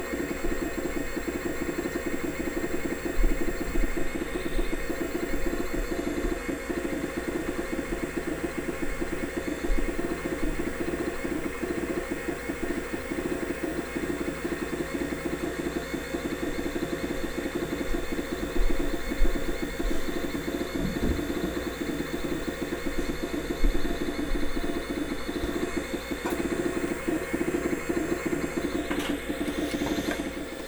It spun up smoothly with no trouble at all and started working right away.
Sound File (1.17MB) of the hard drive being tested by SpinRite for your enjoyment!